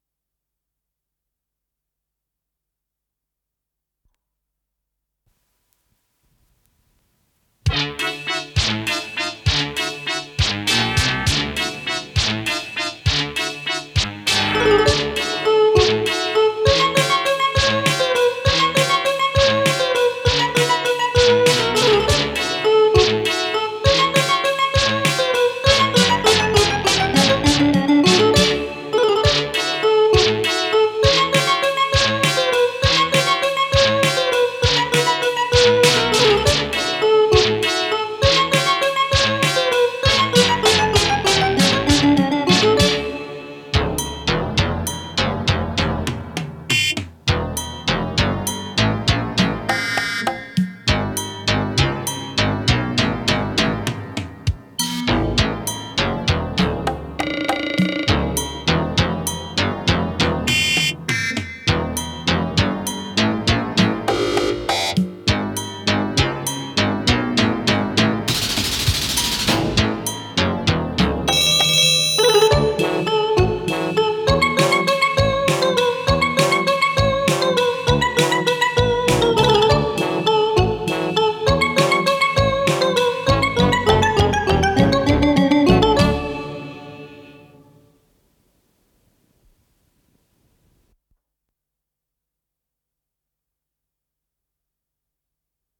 с профессиональной магнитной ленты
ПодзаголовокРе мажор